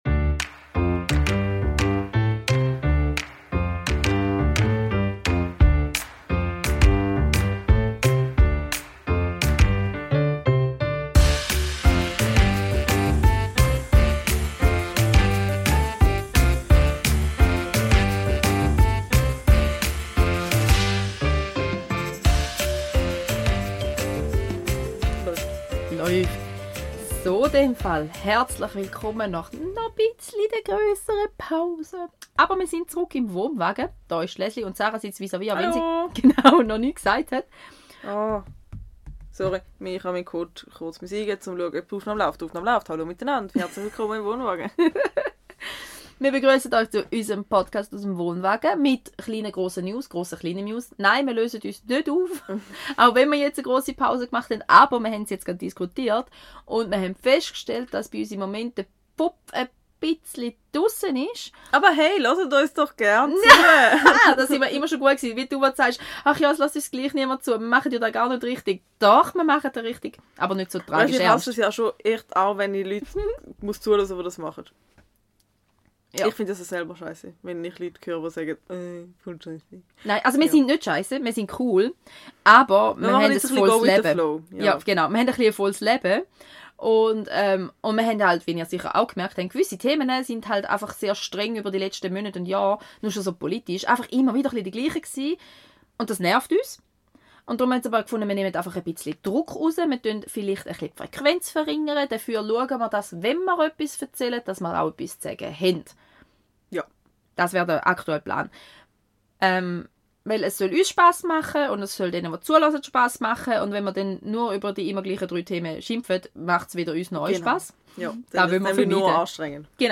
Mit mässiger Tonqualität aber defür pünktlich geht es heute unteranderem um Insekten die wir essen, Raupen die wir pflegen und Gekkos die wir benamsen. Ebenfalls gehen wir auf Wanderung mit höhen und tiefen, welche wir auch im Schul- und Mamialltag erleben.